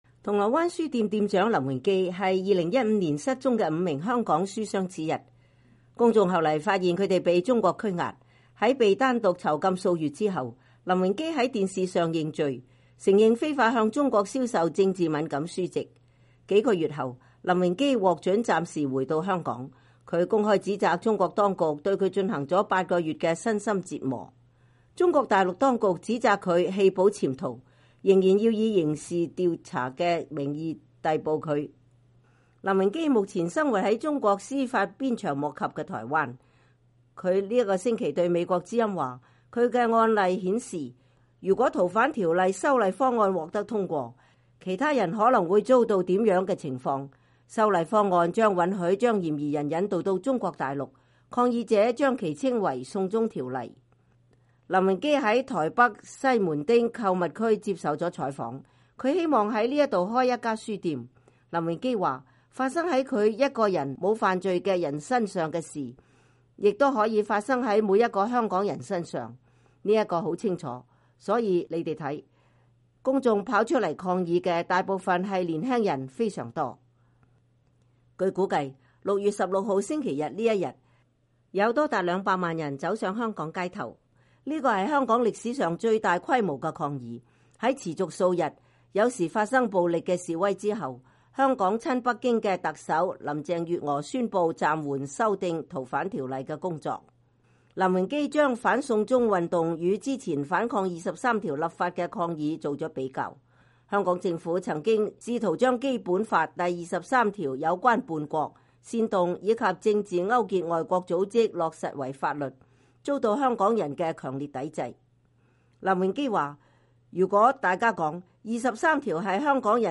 林榮基在台北西門町購物區接受了採訪。